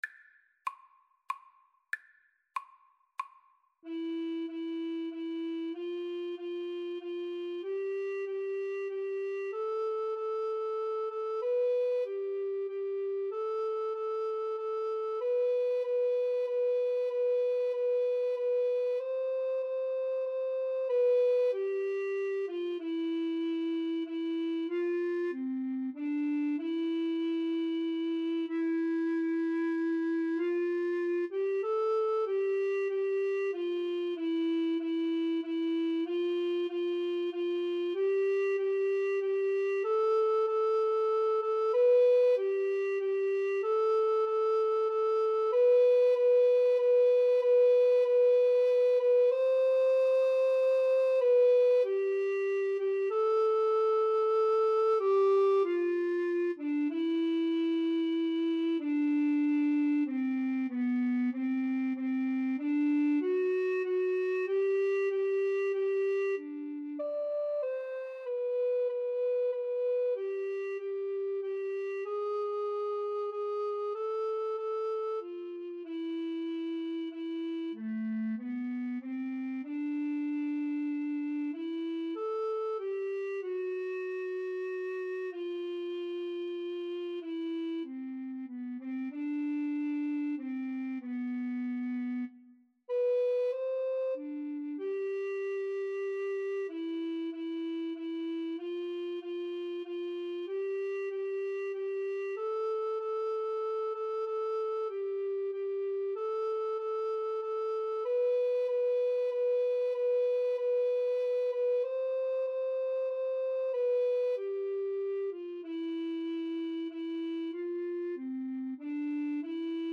=95 Andante
3/4 (View more 3/4 Music)
Classical (View more Classical Recorder Duet Music)